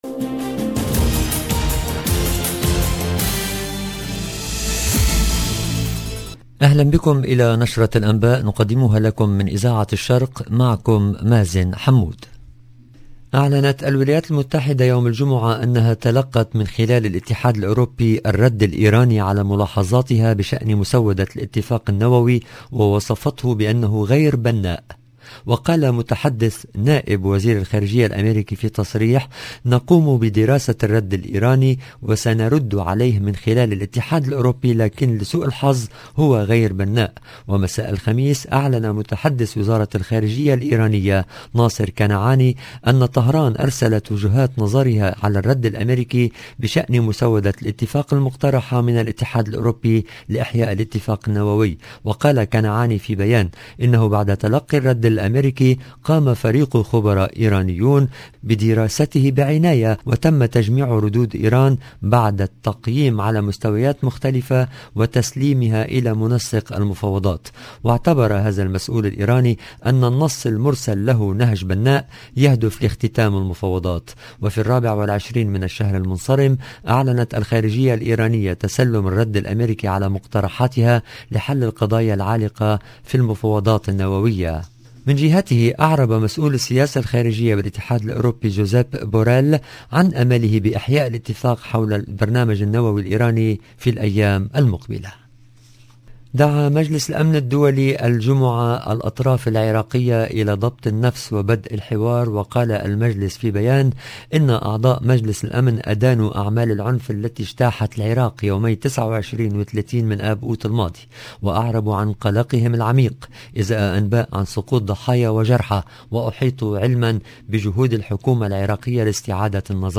LE JOURNAL EN LANGUE ARABE DU SOIR DU 2/09/22